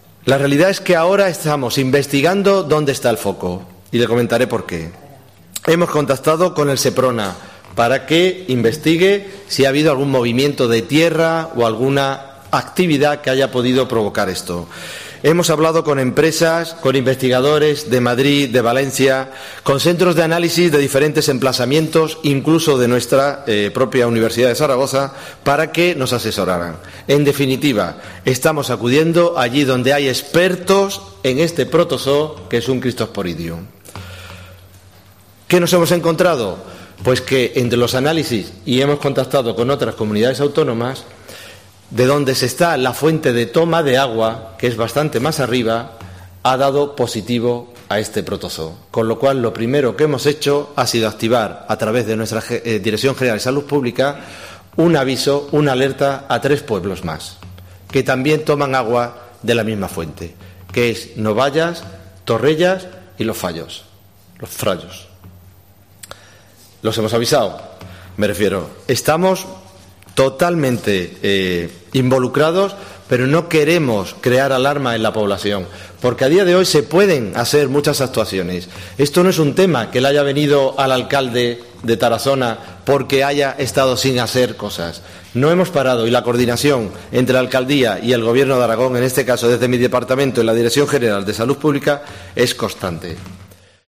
El consejero de Sanidad, José Luis Bancalero, explica las gestiones ante lo ocurrido en Tarazona.